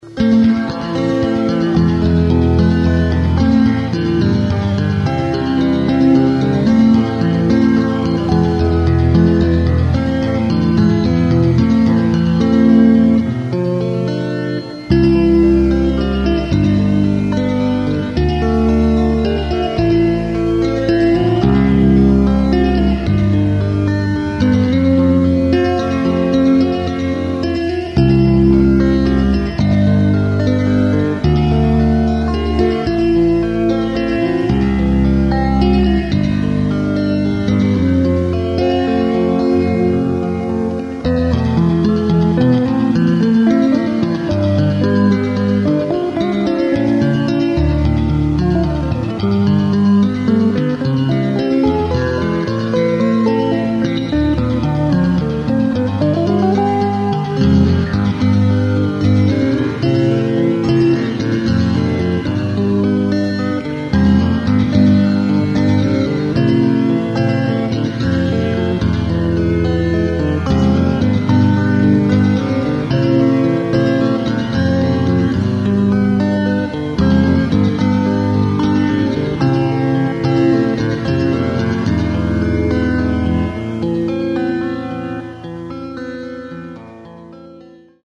Fittingly titled "Divergence", it draws on classical elements and is a guitar and bass duet. I had to try out all the stuff I'd been practicing, so it gets a bit busier than need be in parts...